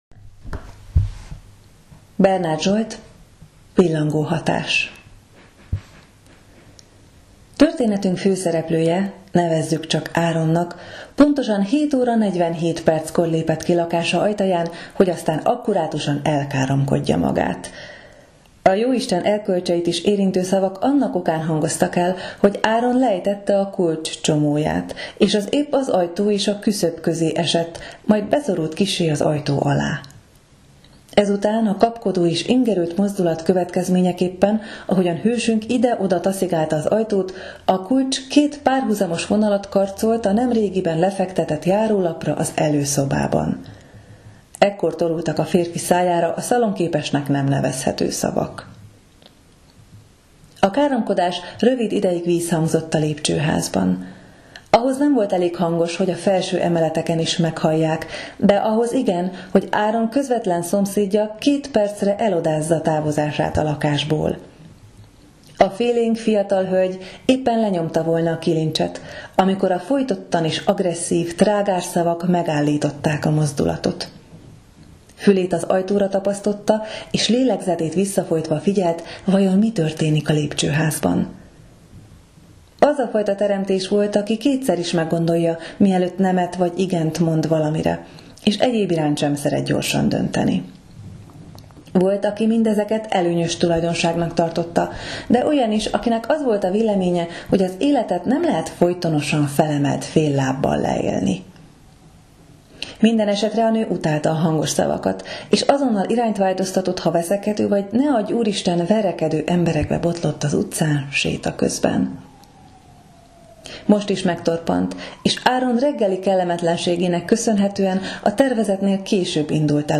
Ajánló és ajándék hangosnovella
De hogy kicsit jobban beleláss, felolvastam neked az egyik novelláját.